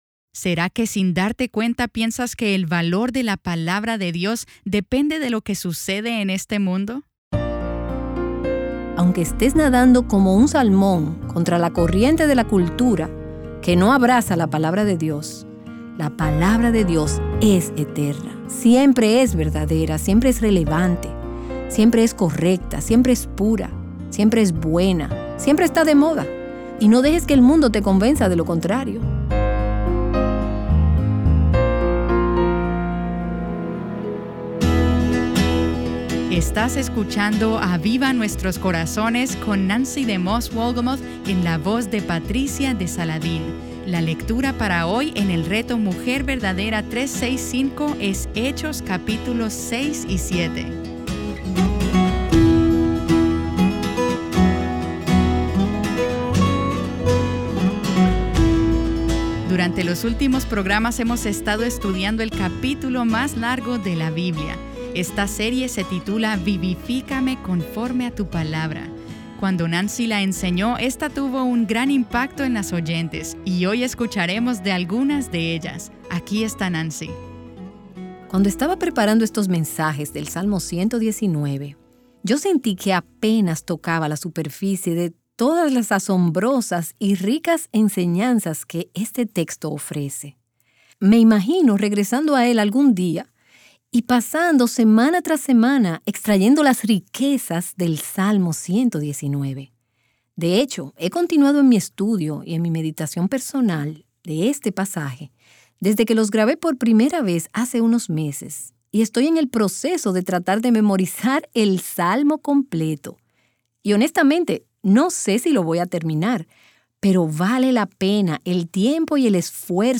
Escuchemos algunos testimonios de mujeres sobre su experiencia a través del Salmo 119, y cómo este les ha ministrado en sus tiempos de necesidad.